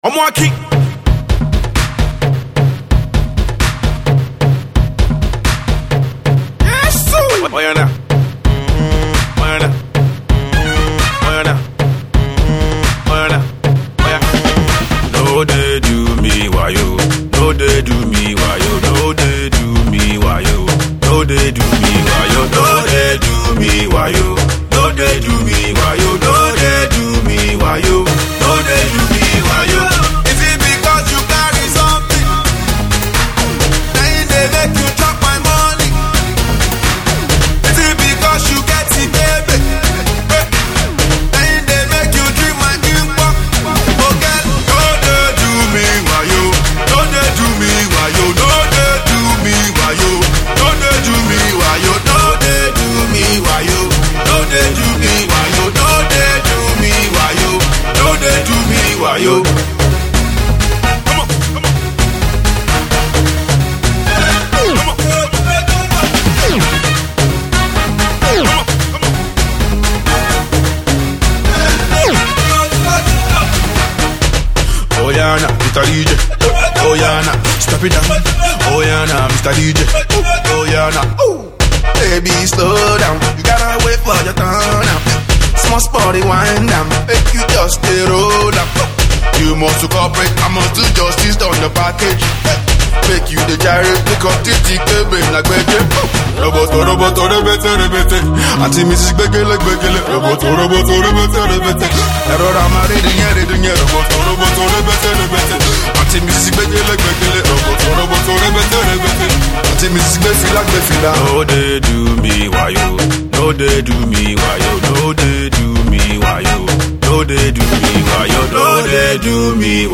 club banging tune